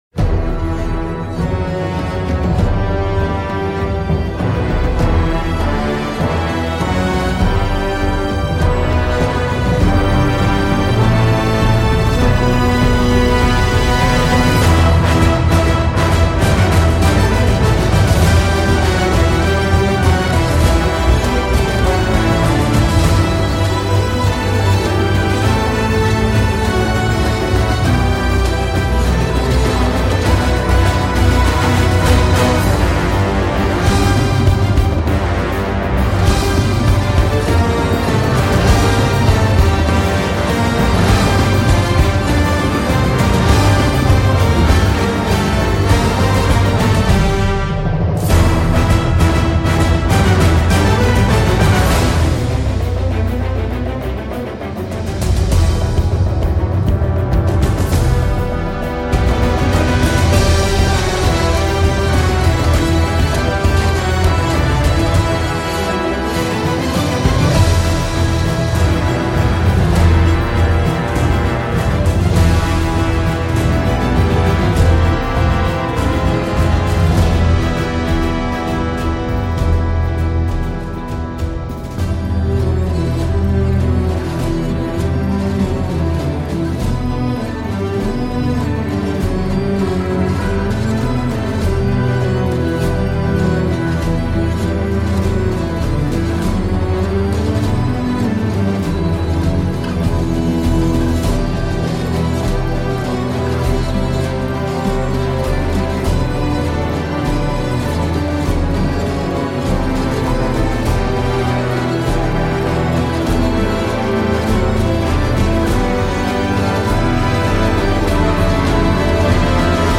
Et voilà un thème entêtant !